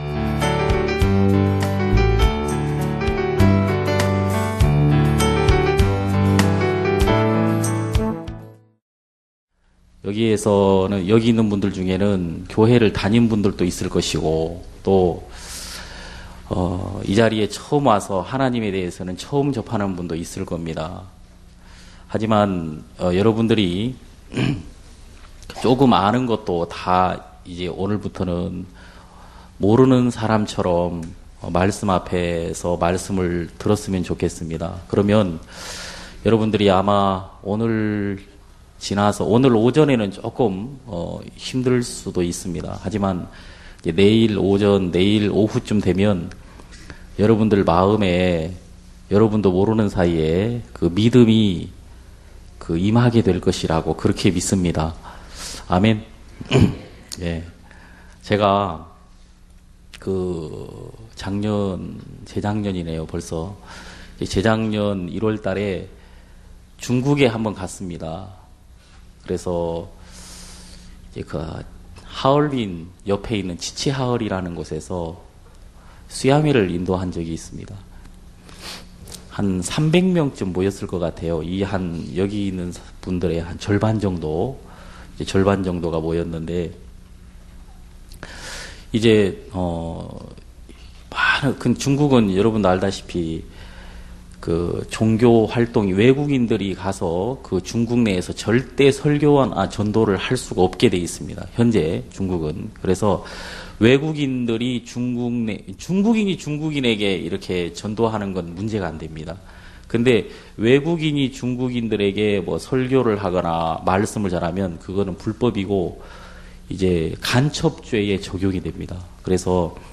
알기쉬운 복음강해